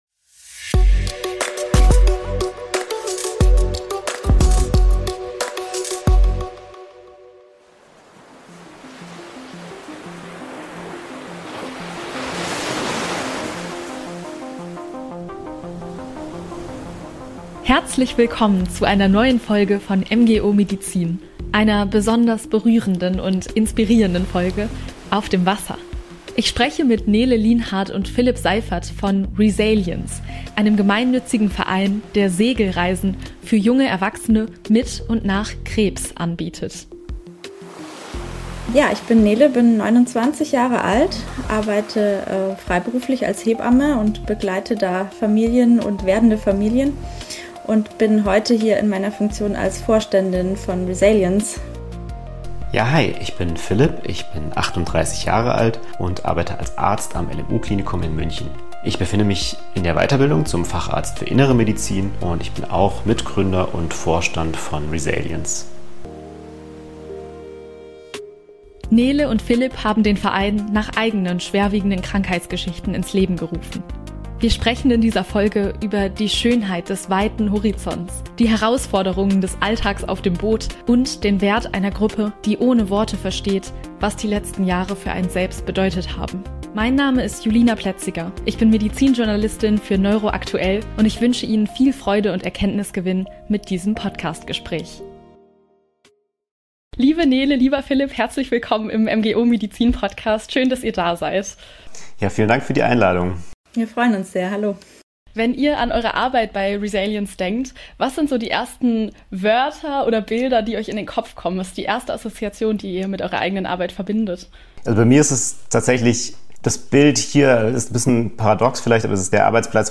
Expertentalk